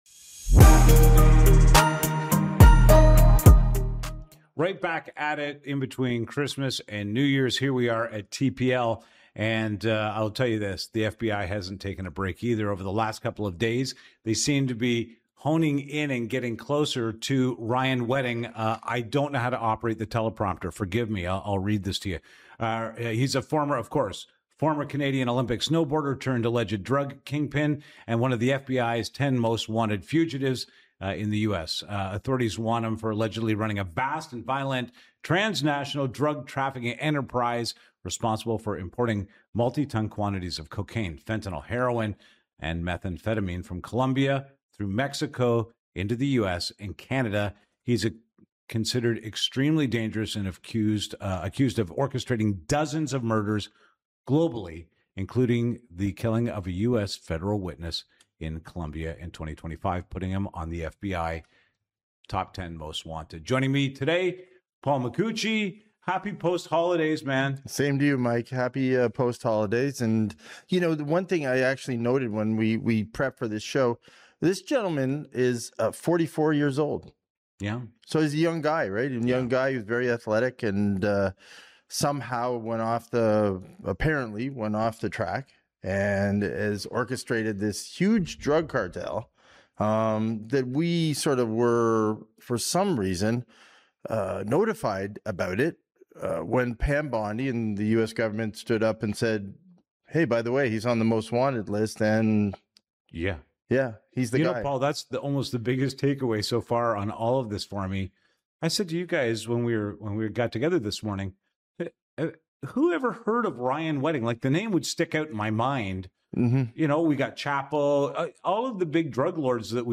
From organized crime links to cross-border law enforcement coordination, this conversation exposes how deep the case really goes and why Canada largely missed the warning signs until American officials stepped in.